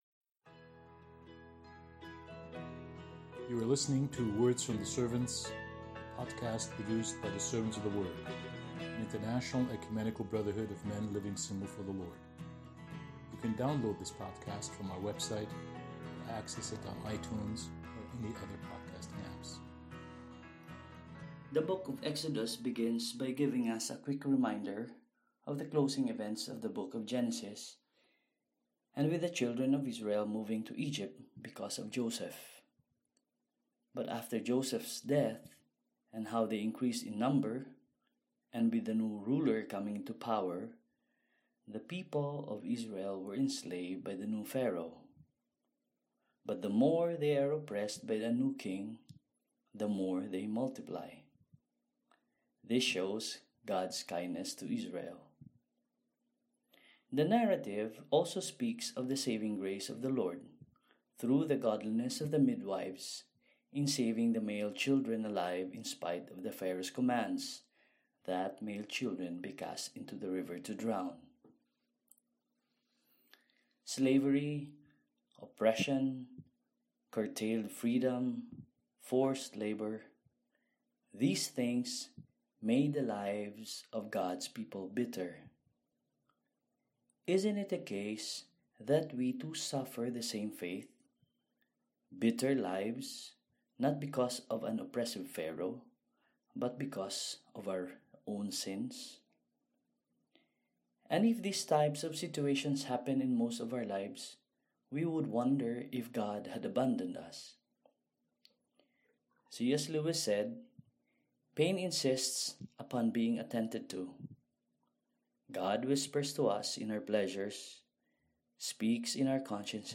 commentary on Exodus 1